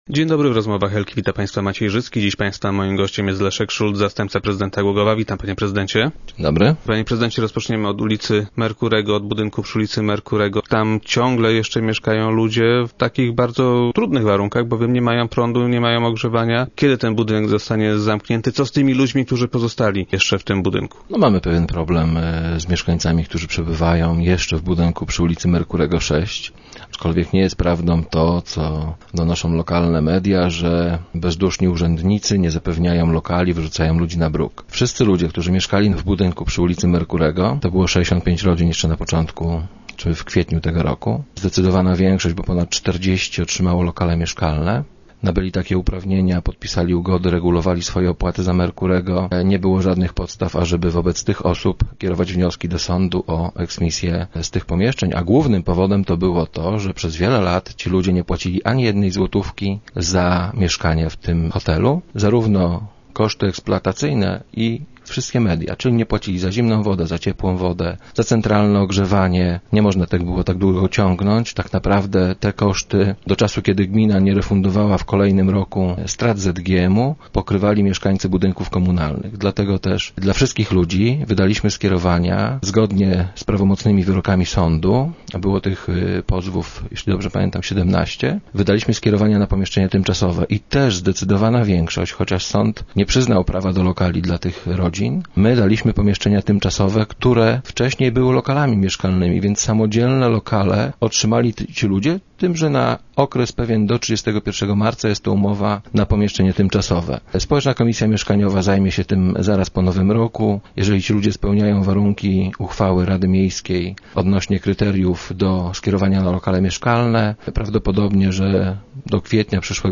- Nie chcemy ich wyrzucać na bruk. Proponujemy im lokale tymczasowe - twierdzi Leszek Szulc, zastępca prezydenta Głogowa, który był dziś gościem Rozmów Elki.